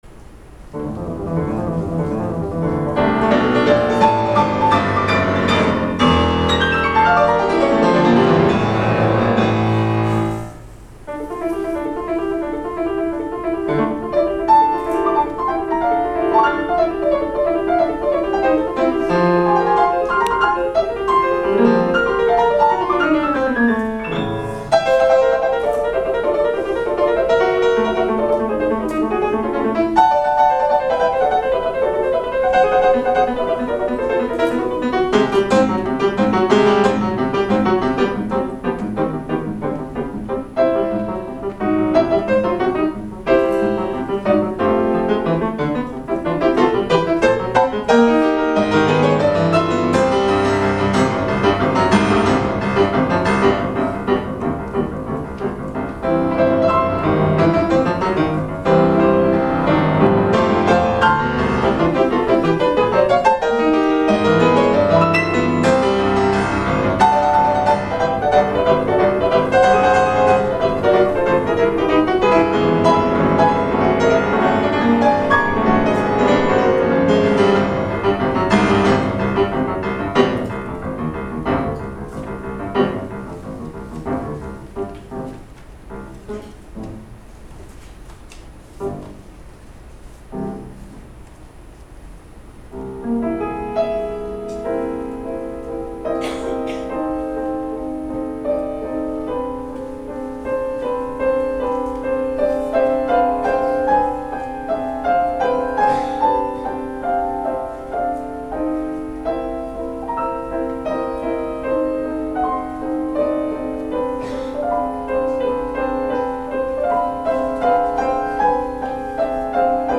久しぶりにシンフォニーではなく、天神山文化プラザで行いましたが、 ちょっと勝手が違ったせいか、不手際が多々有り、反省だらけの発表会となりました。